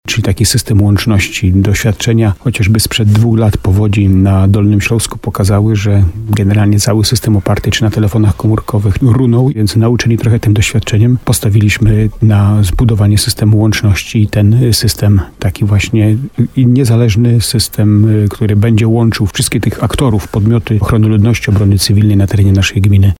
W programie Słowo za Słowo na antenie RDN Nowy Sącz burmistrz Jacek Lelek podkreślał też, że udało się wzmocnić system telekomunikacji.